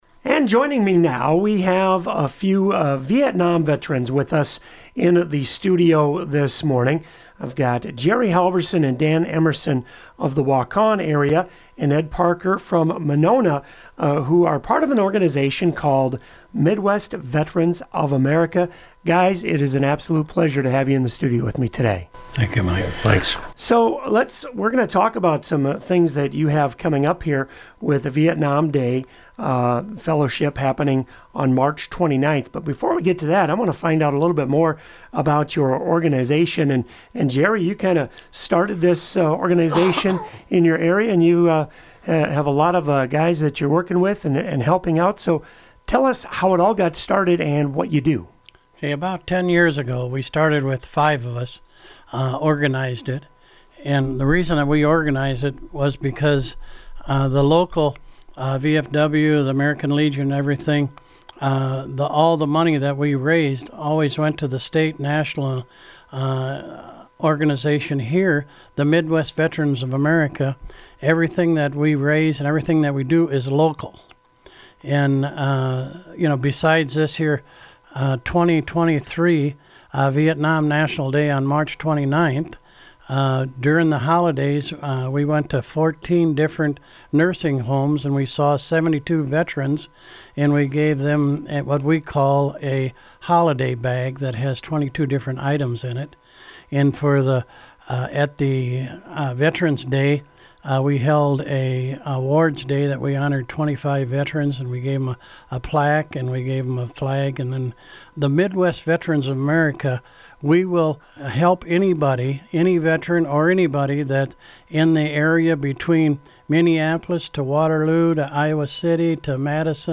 Listen: Interview with Midwest Veterans of America Members